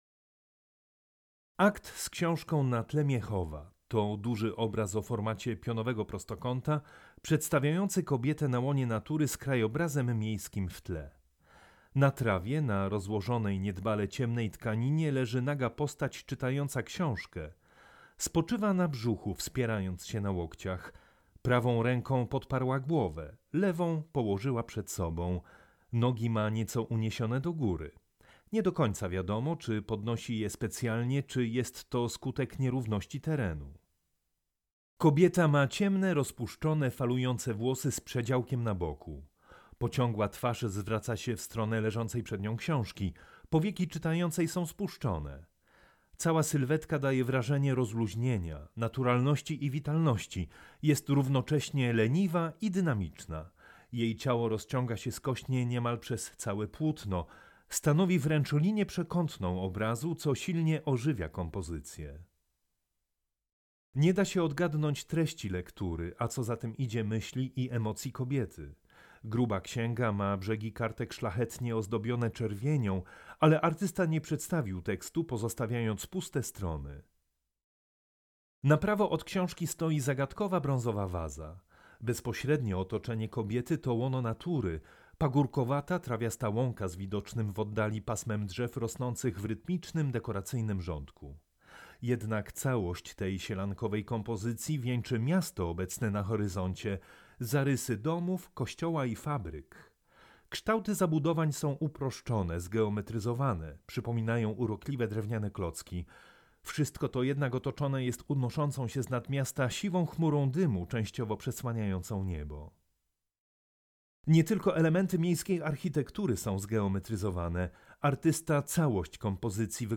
AUDIODESKRYPCJA
AUDIODESKRYPCJA-Zbigniew-Pronaszko-Akt-z-ksiazka-na-tle-Miechowa.mp3